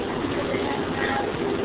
Is she saying something about a mouse???